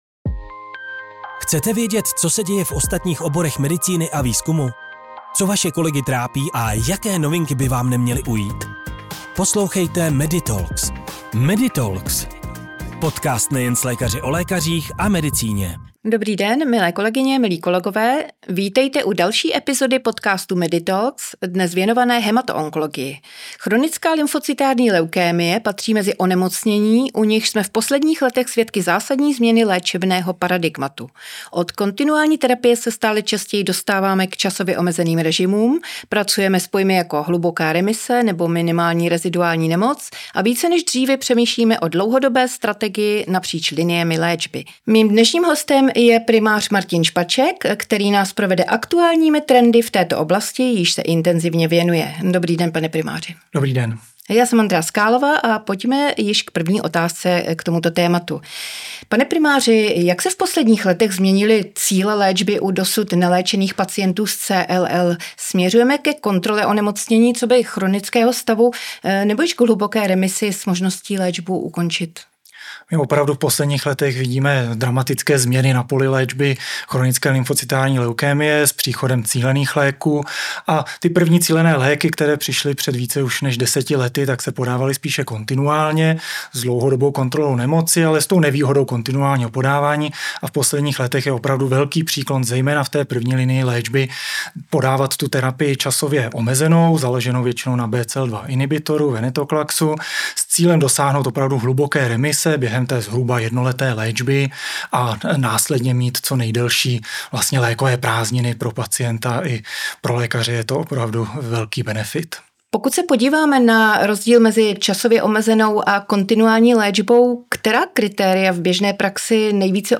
Jakou pozici mají inhibitory Bcl-2 v 1. linii? Kdy volit kontinuální terapii inhibitory BTK? A jak přistupovat k vysoce rizikovým pacientům nebo zahájení léčby venetoklaxem v praxi? Odpovědi nabízíme v první části rozhovoru